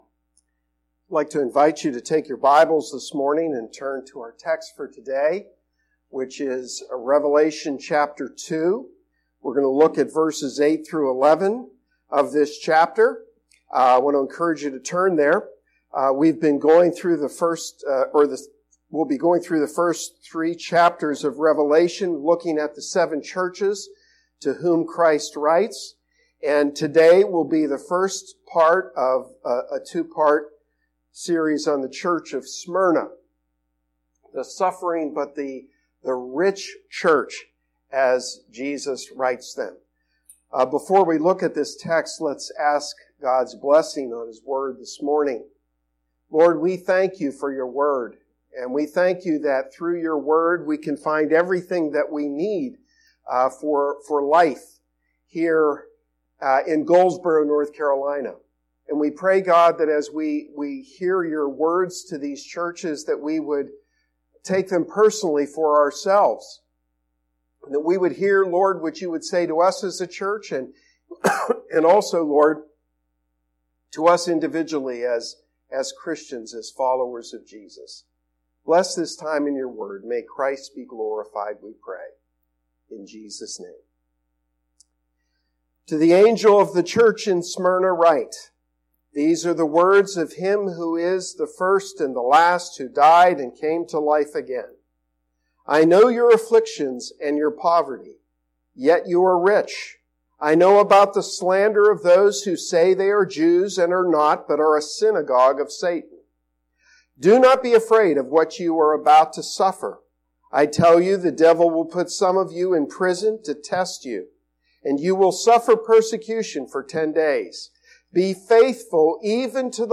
Sermon-2-10-19.mp3